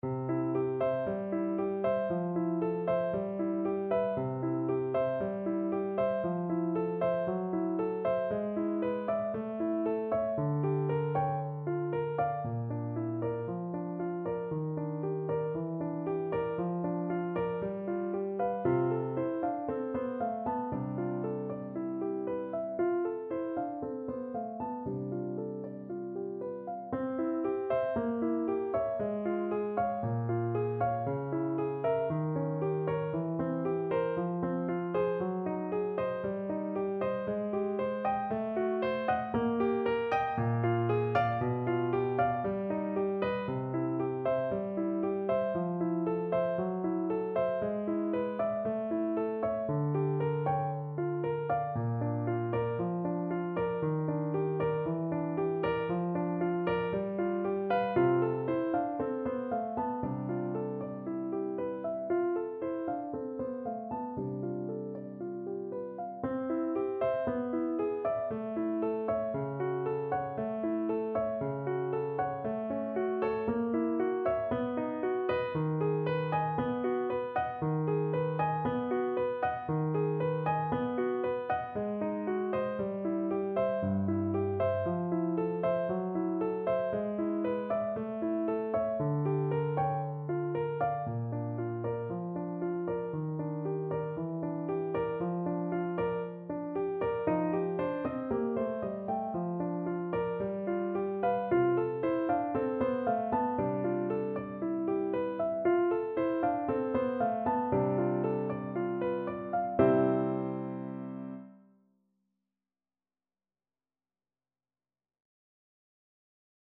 4/4 (View more 4/4 Music)
Allegro moderato =116 (View more music marked Allegro)
Classical (View more Classical Flute Music)